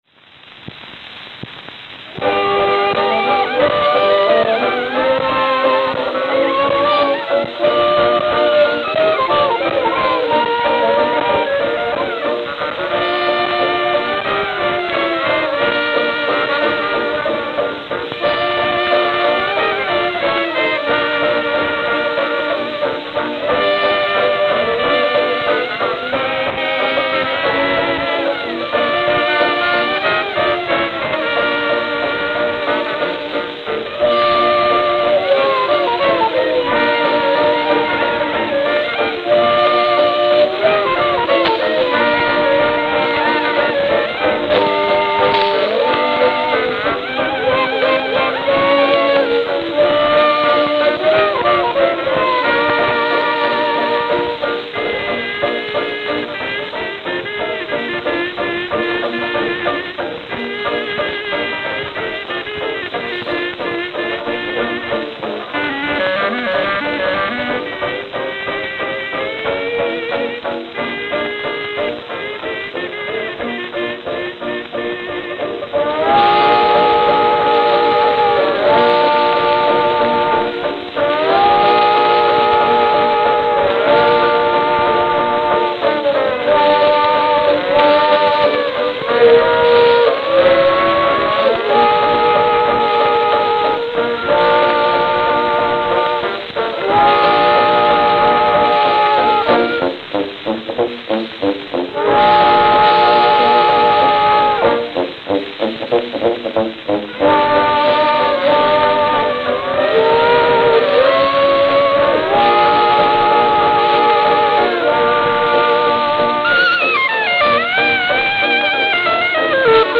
Note: Worn with pressing issue at beginning.